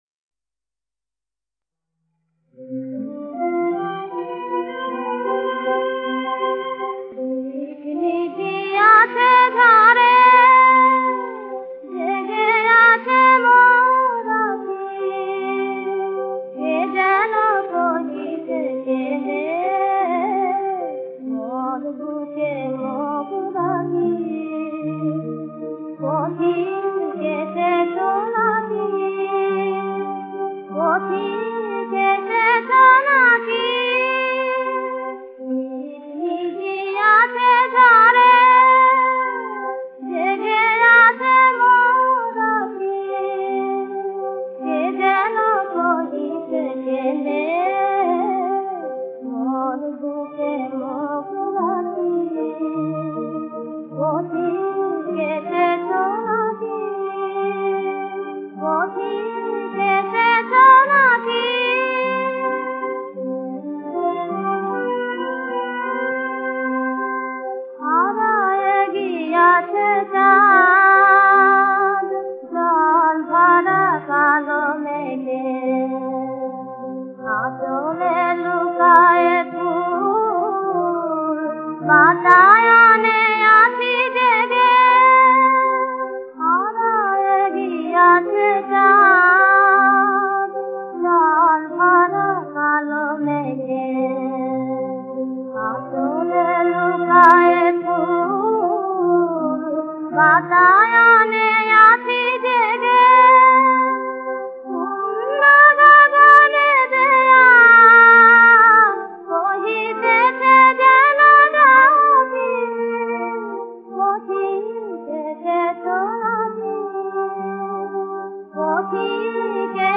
নজরুল সঙ্গীত